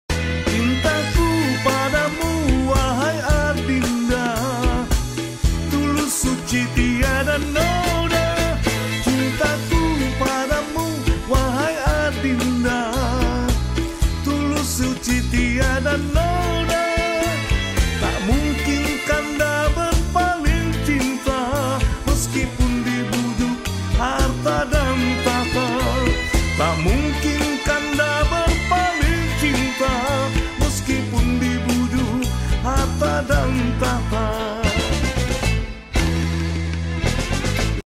penuh emosi
CoverSong